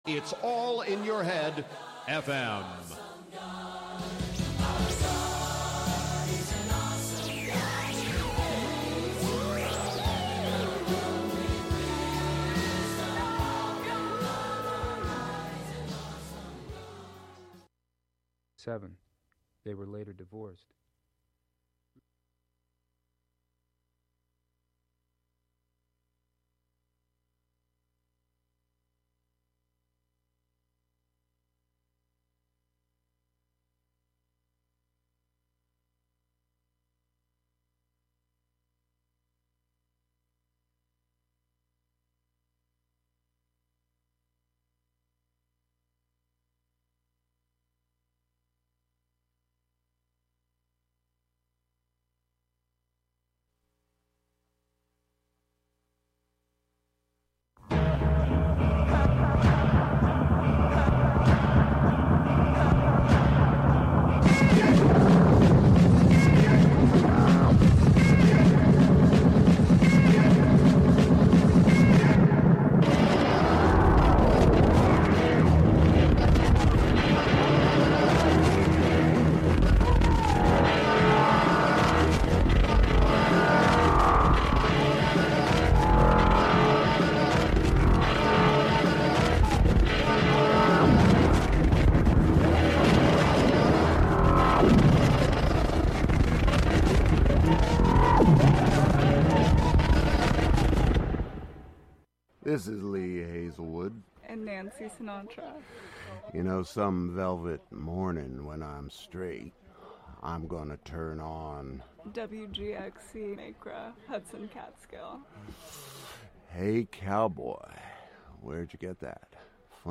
just the fictions read for the listener as best as i can read them.